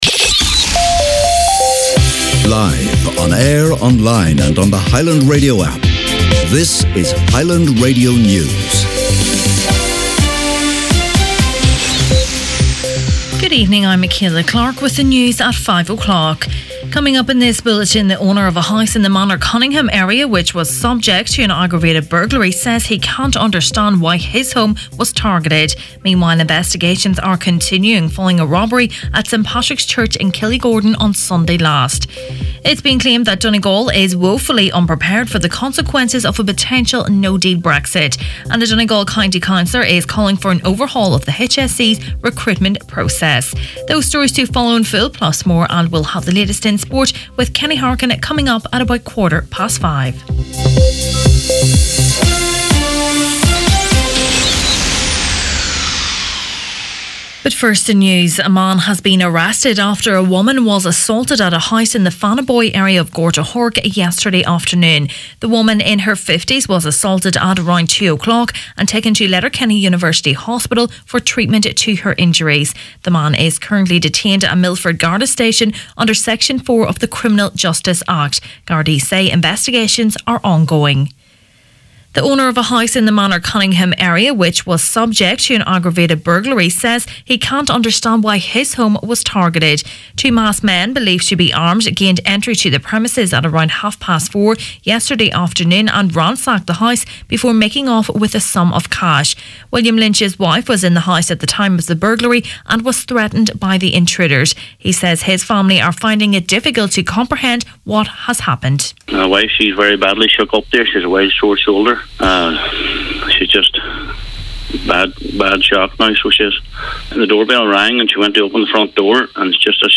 Main Evening News, Sport and Obituaries Thursday February 28th